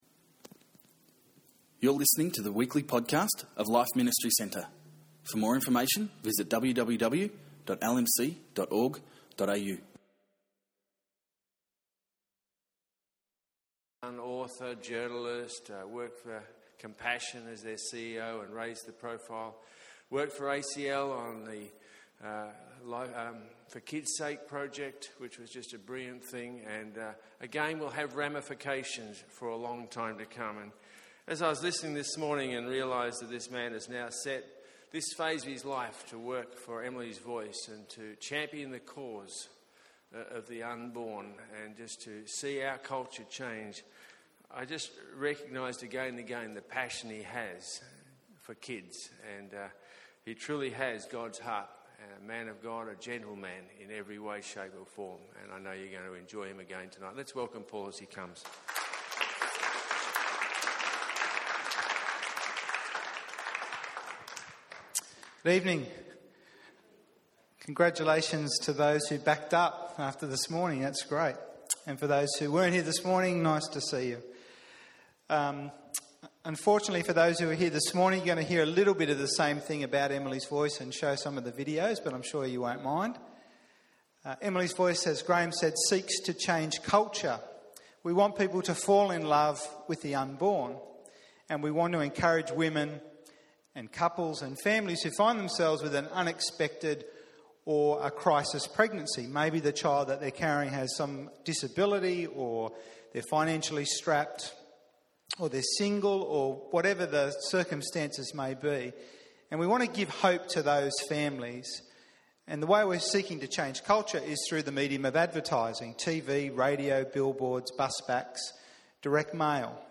A great Q&A session followed.